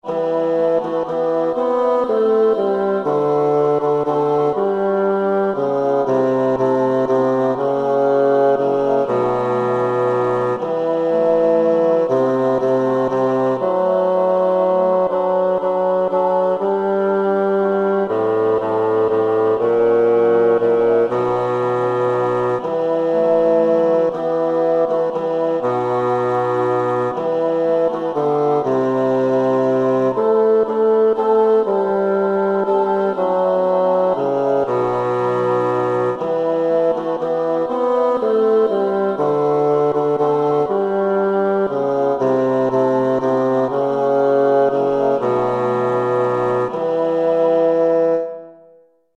Ande och Liv bas
ande av liv_bas.mp3